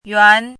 怎么读
yuán
yuan2.mp3